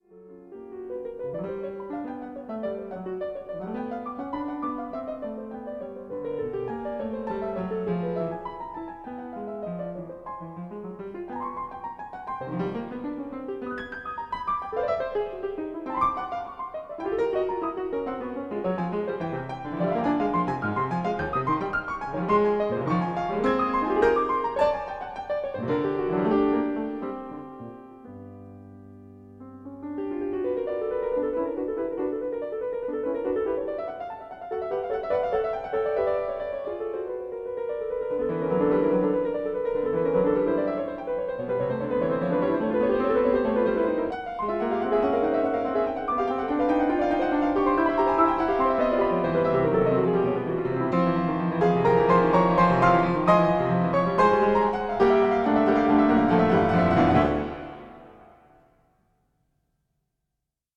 Stereo
Recorded 1973-4 at St George the Martyr, Holborn, London UK